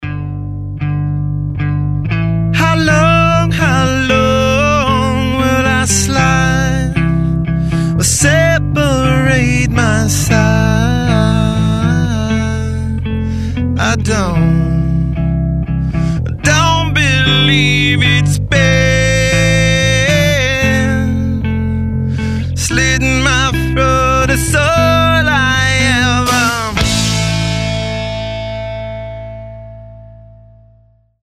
• Качество: 320, Stereo
мужской вокал
Alternative Rock